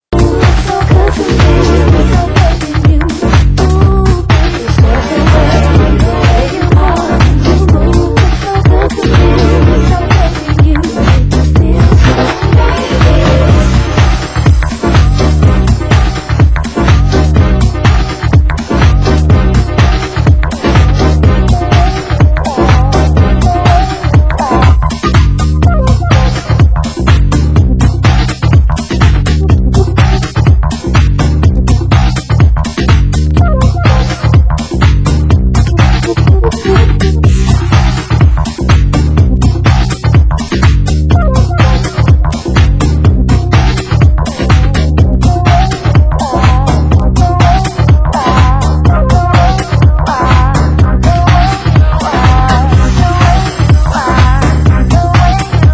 Genre: UK House
vocal
dub